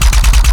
Added more sound effects.
GUNAuto_RPU1 Loop_02_SFRMS_SCIWPNS.wav